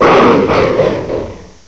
cry_not_drampa.aif